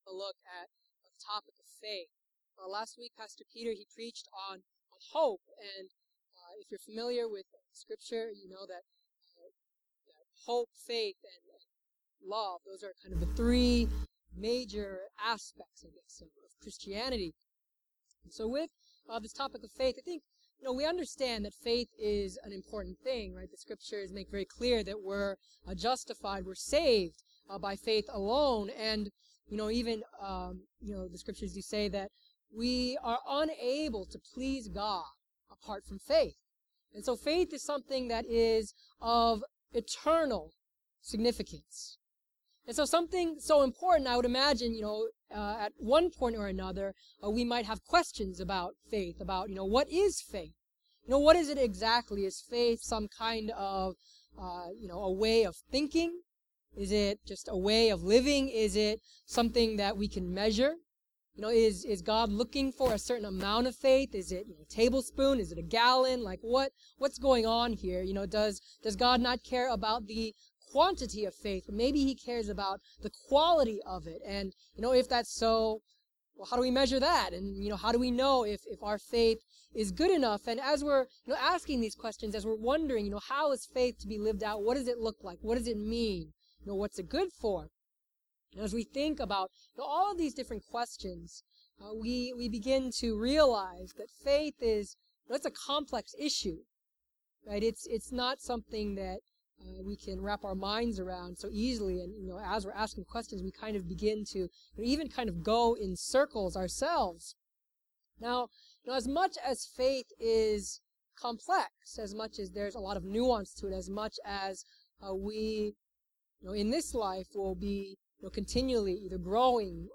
Miscellaneous Sermons | Kairos Church